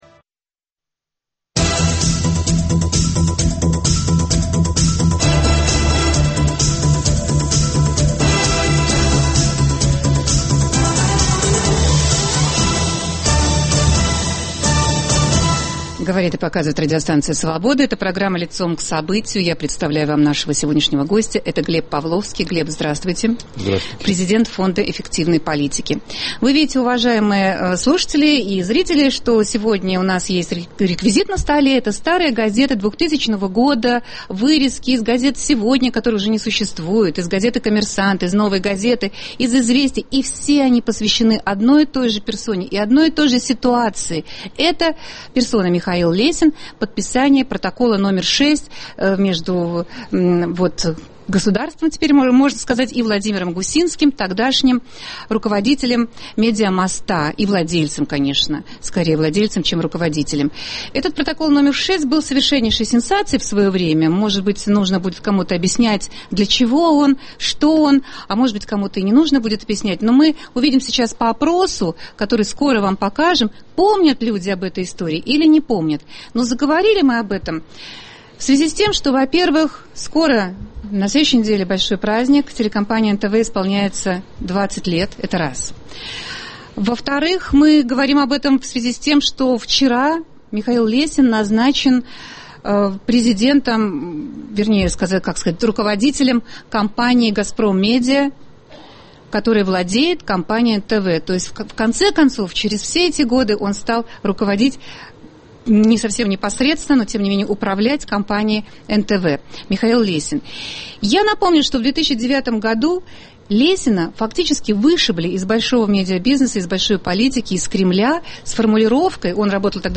Гость студии - президент Фонда эффективной политики Глеб Павловский.